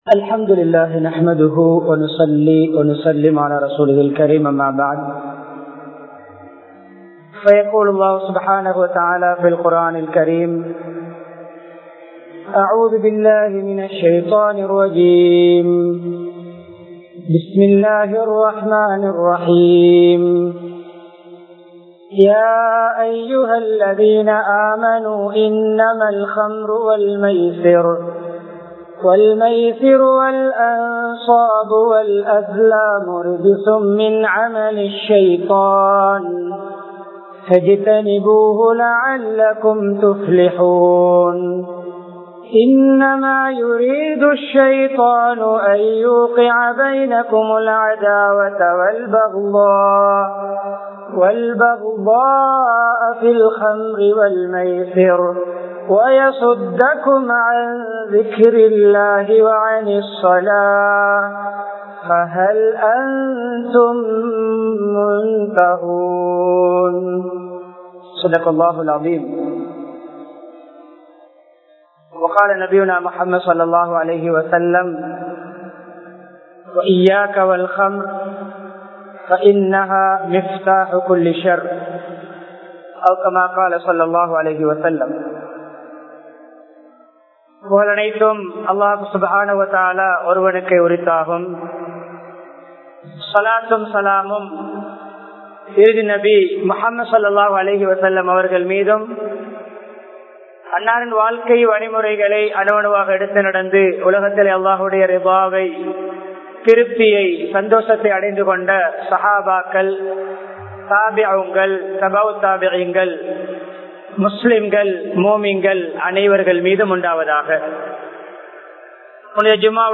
போதைவஸ்துவின் விளைவுகள் | Audio Bayans | All Ceylon Muslim Youth Community | Addalaichenai
Trincomalee, NC Road Jumua Masjidh